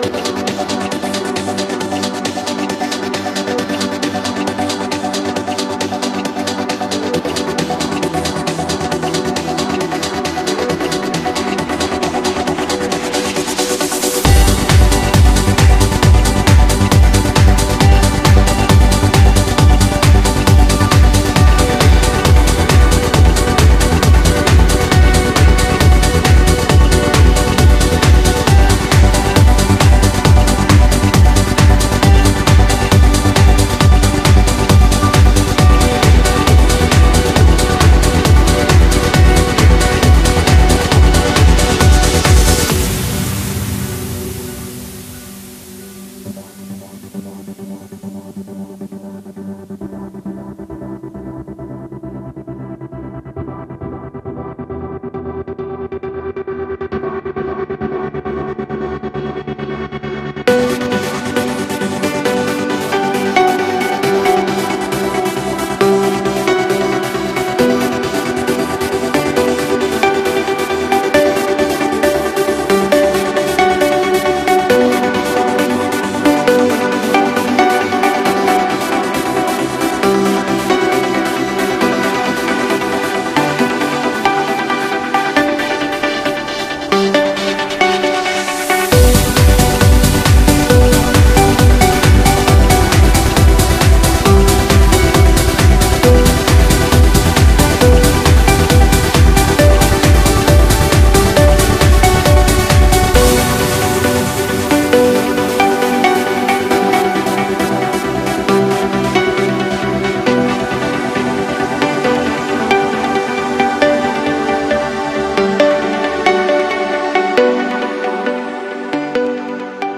BPM135
Audio QualityPerfect (High Quality)
Comments[EPIC TRANCE]